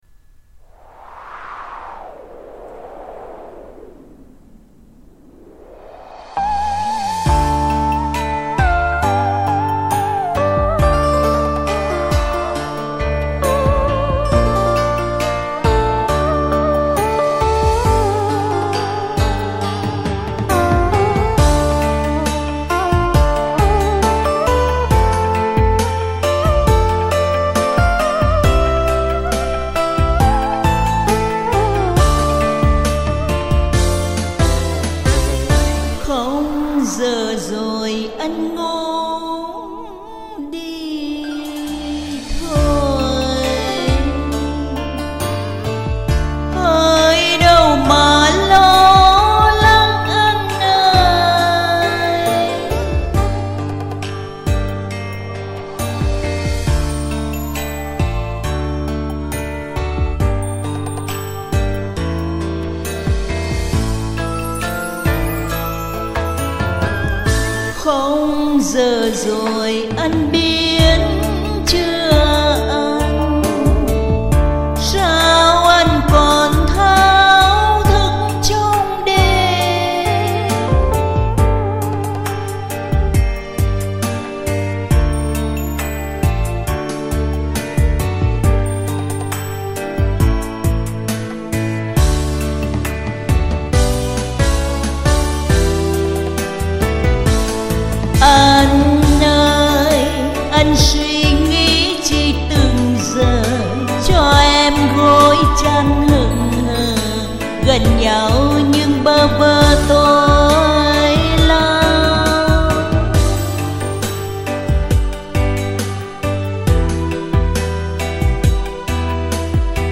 Tôi và bạn tôi đã song ca ca khúc